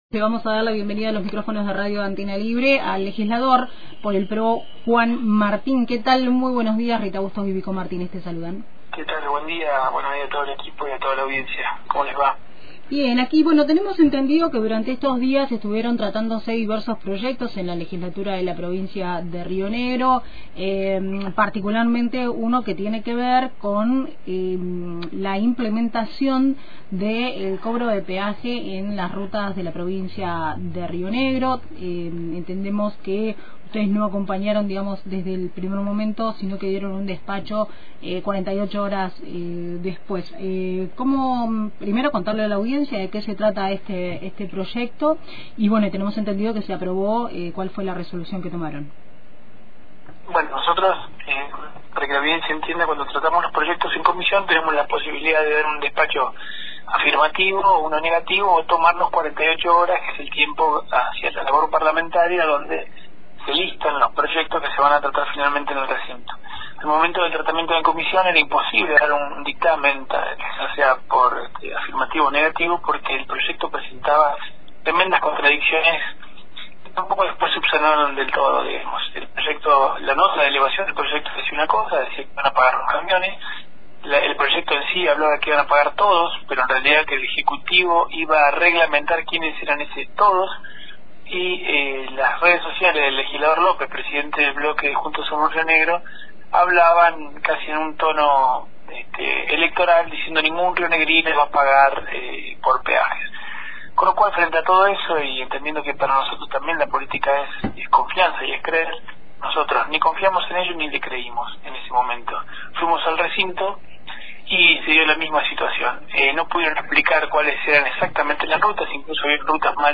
El Legislador del PRO, Juan Martín, dialogó con Antena Libre acerca de la aprbación del Proyecto que implementa el cobro de peaje en la provincia de Río Negro.